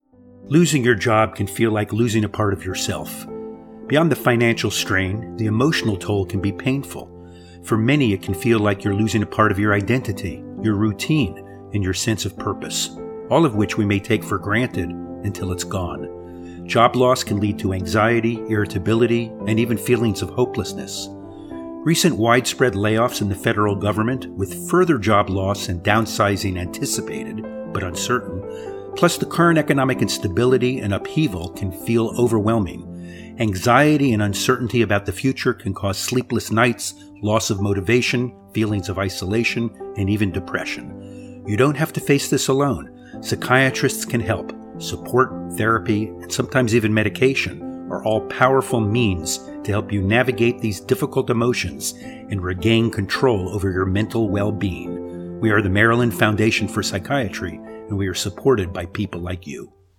Latest Public Service Radio Minute